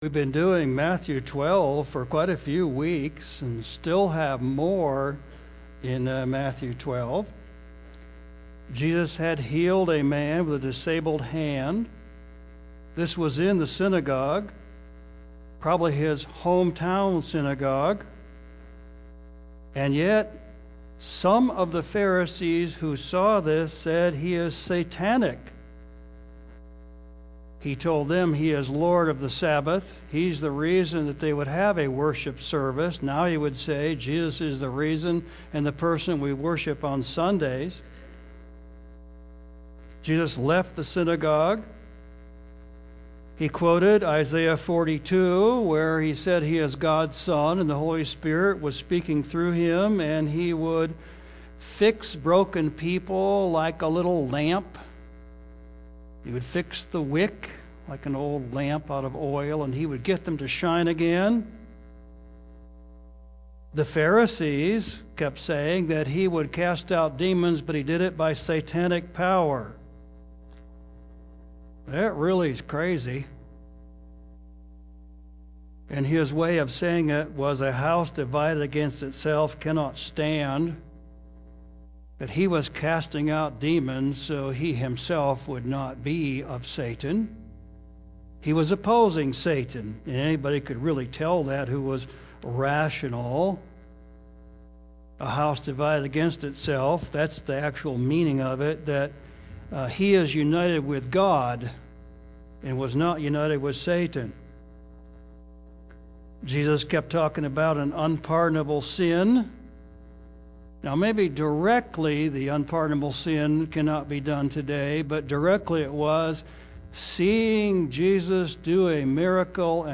Latest audio sermon from Sunday, Sep 21st 2025